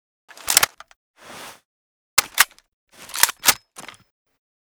9a91_reload_empty.ogg